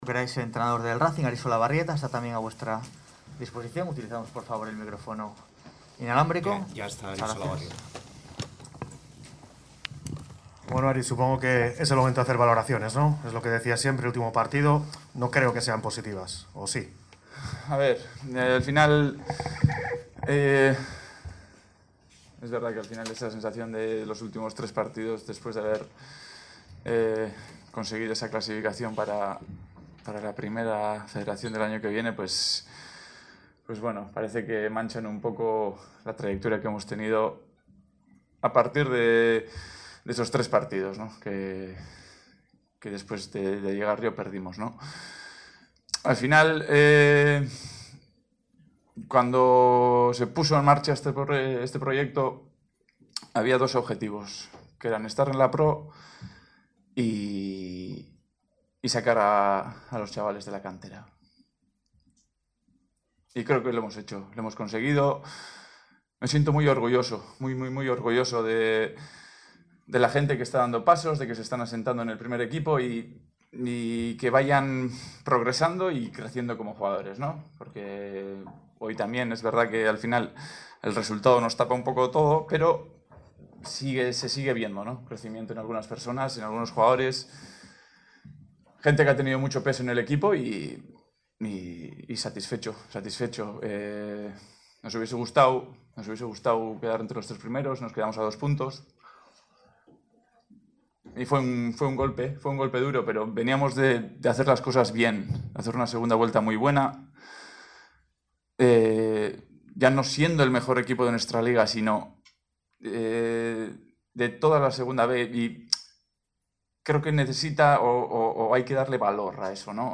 rueda_de_prensa_de_A.mp3